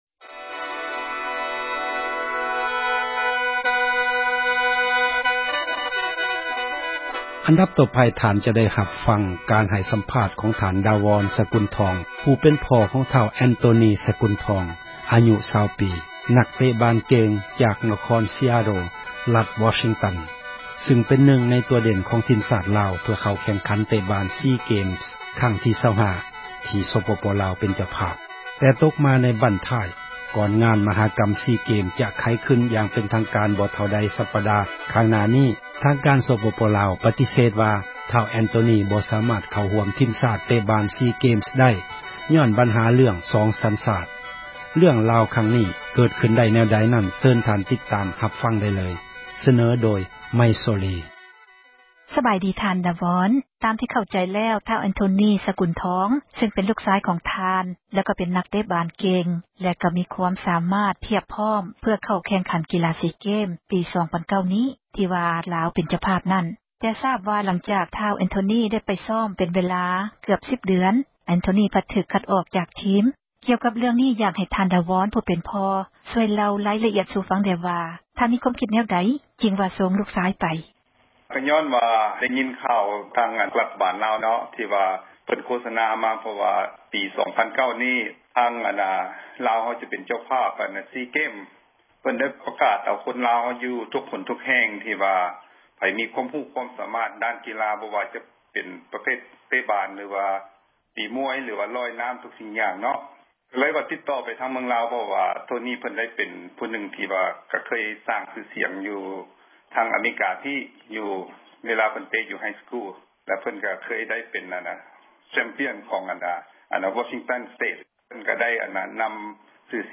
ການໃຫ້ສັມພາດ